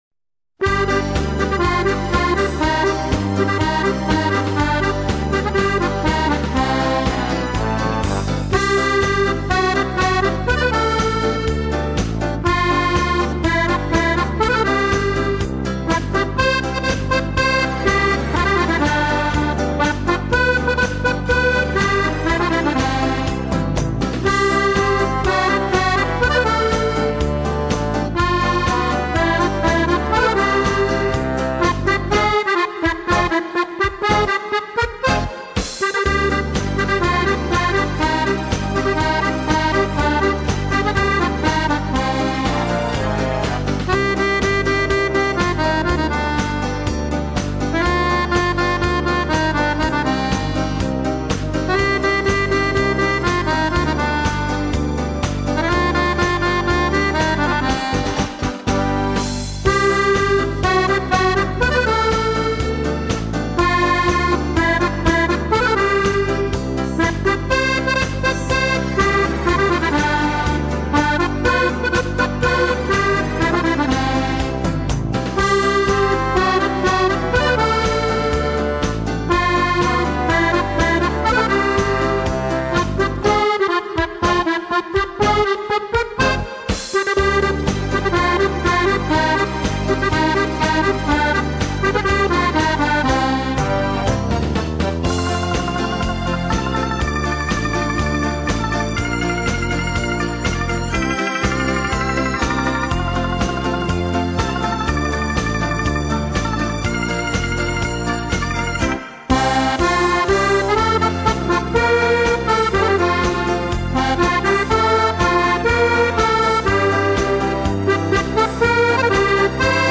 в исполнении аккордеона